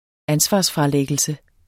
Udtale [ ˈansvɑs- ]